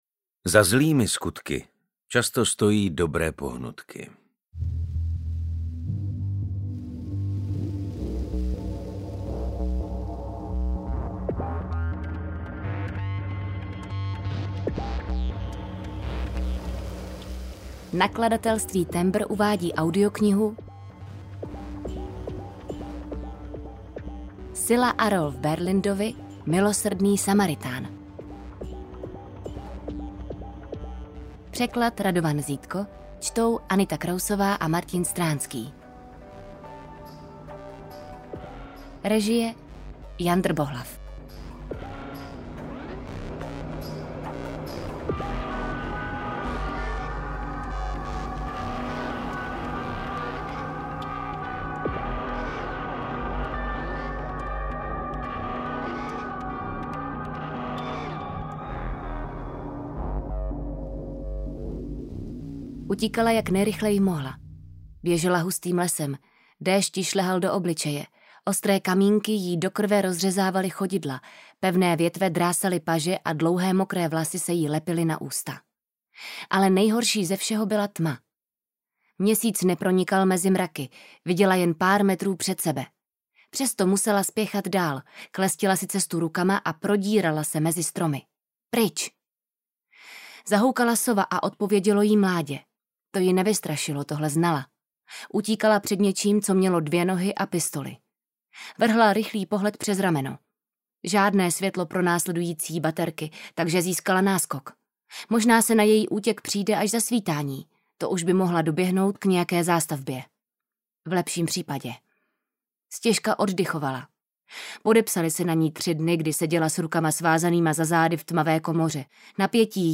UKÁZKA Z KNIHY
audiokniha_milosrdny-samaritan_ukazka.mp3